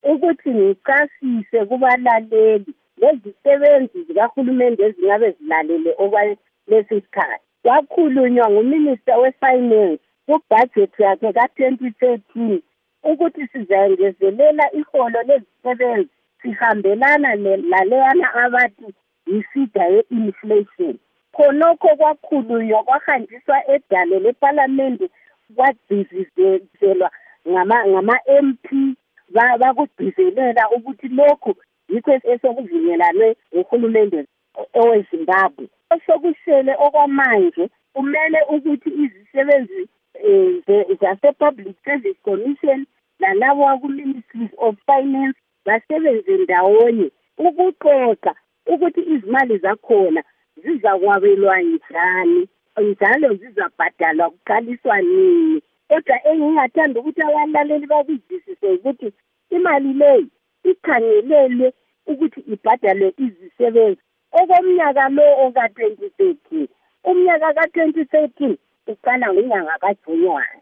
Ingxoxo Esiyenze LoNkosikazi Lucia Matibenga